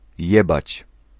Ääntäminen
IPA: [ˈjɛbat͡ɕ]